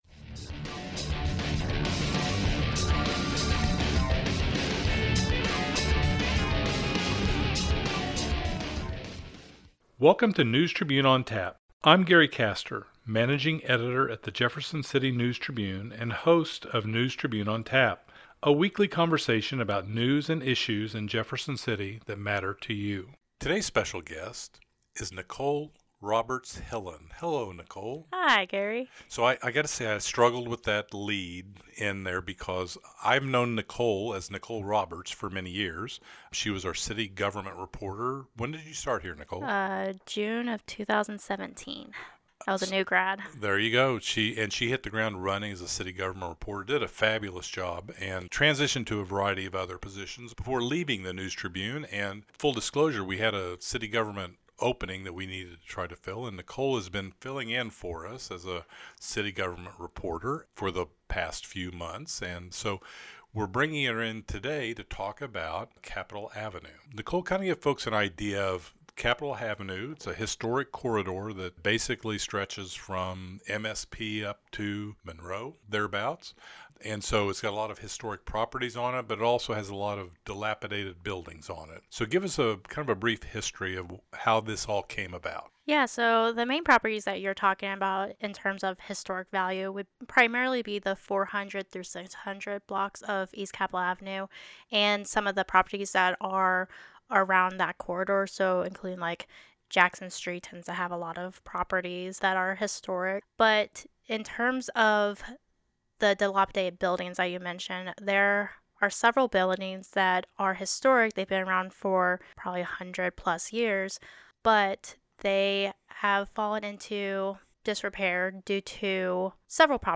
chat about the demolition, repairs and redevelopment of properties along East Capitol Avenue.